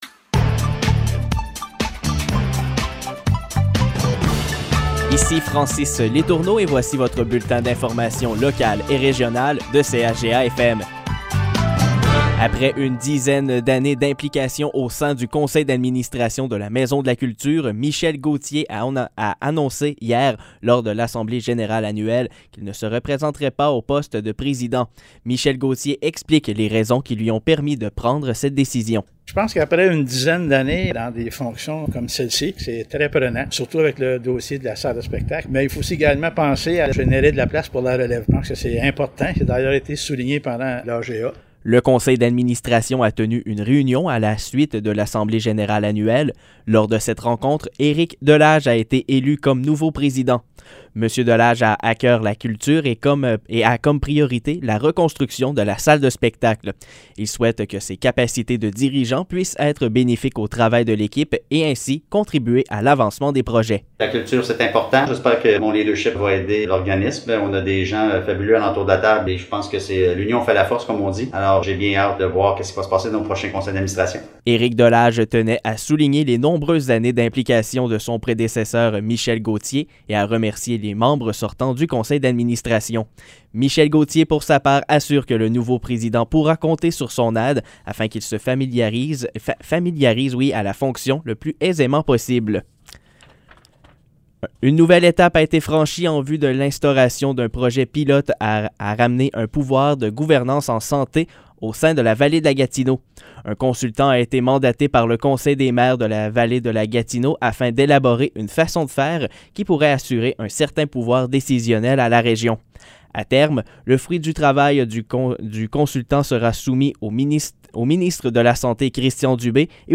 Nouvelles locales - 19 octobre 2021 - 15 h